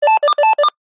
Звуки разговоров по рации
Рации и звонки звук рации звонка